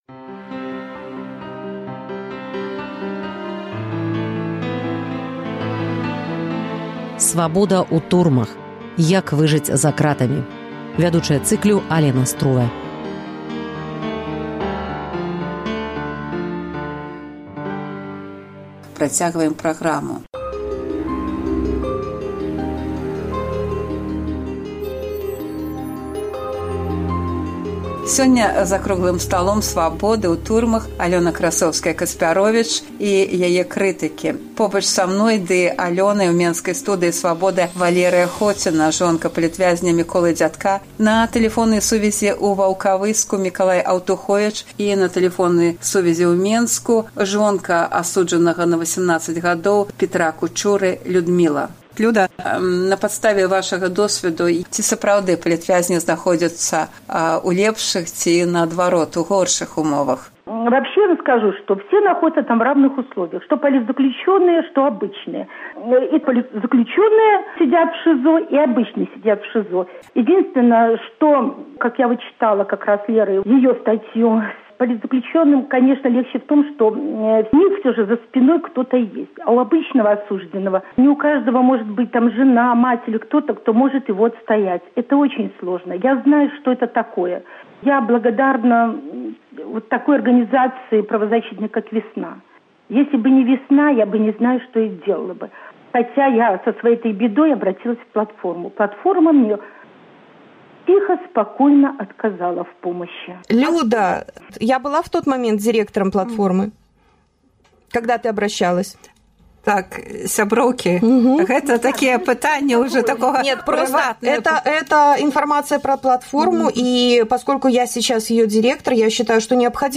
Свабода ў турмах. Круглы стол. Частка 2
Менск, 13 чэрвеня, 2015